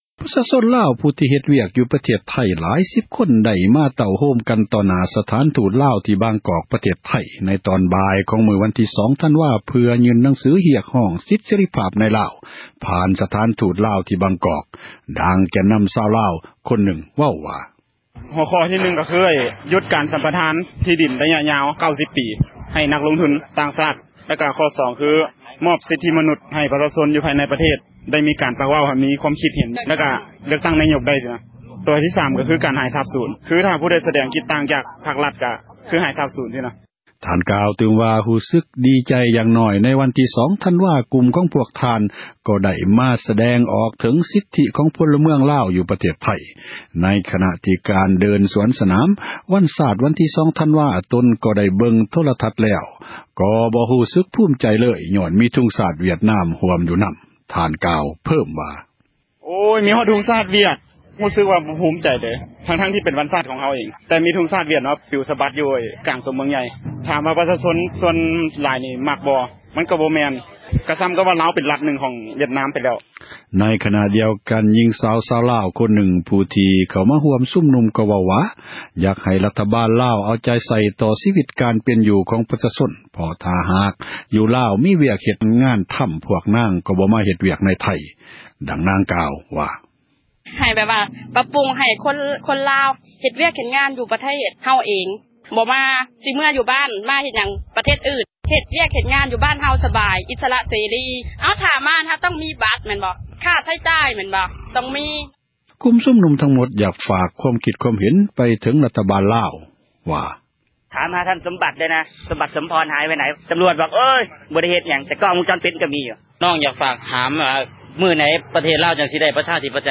F-workers ເນື່ອງໃນວັນຊາດ ຊາວລາວໃນໄທ ຍື່ນໜັງສືຮຽກຮ້ອງ ສິດເສຣີພາບໃນລາວ ຢູ່ສະຖານທູດລາວ ປະຈໍາ ບາງກອກ